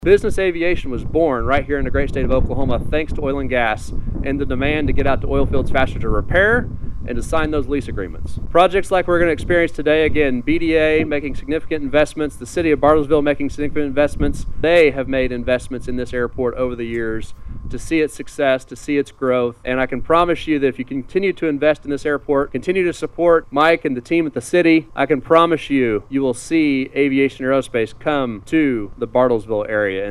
Dozens of citizens and aviation leaders held a ceremony Monday morning at Bartlesville Municipal Airport to celebrate the completion of 1,300 feet of new taxiway and the groundbreaking of a new 20,000-square-foot hanger.
Grayson Ardies, the executive director of the Oklahoma Aerospace and Aeronautics Commission, says this is a big day for